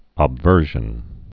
(ŏb-vûrzhən, əb-)